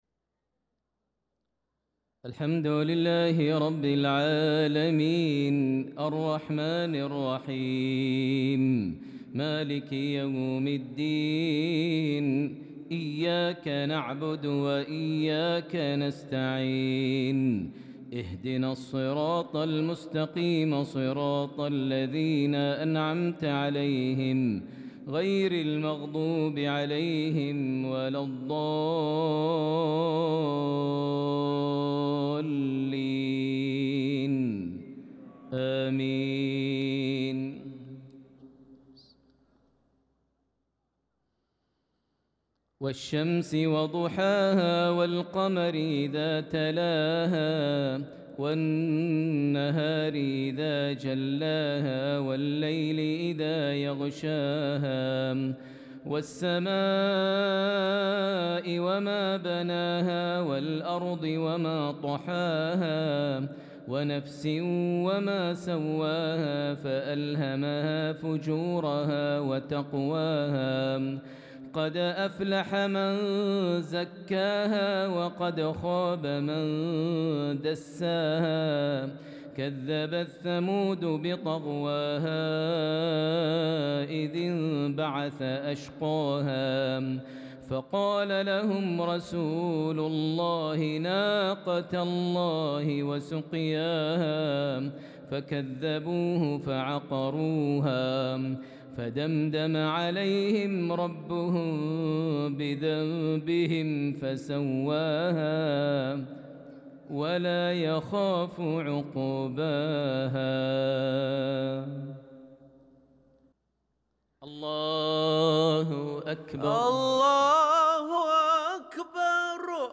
صلاة المغرب للقارئ ماهر المعيقلي 28 ذو الحجة 1445 هـ
تِلَاوَات الْحَرَمَيْن .